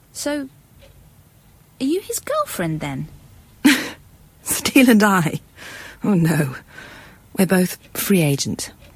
Audio drama
Memorable Dialog